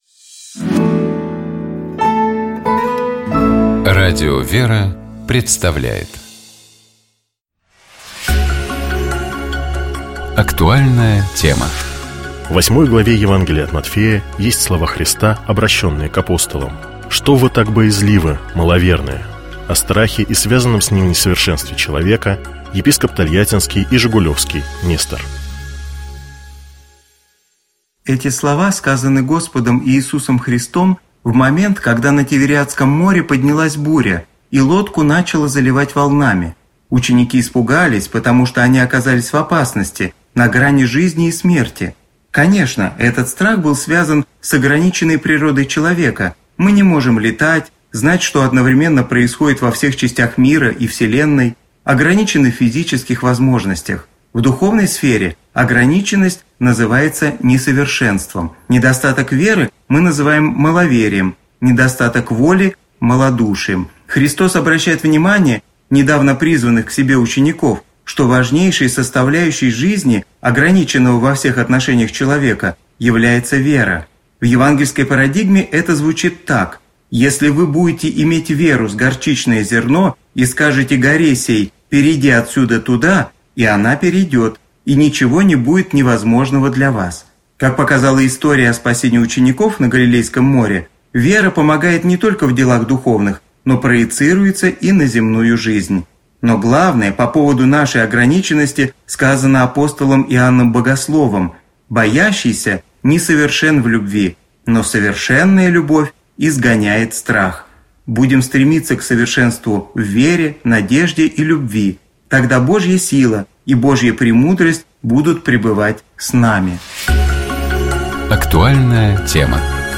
О страхе и связанном с ним несовершенстве человека, — епископ Тольяттинский и Жигулёвский Нестор.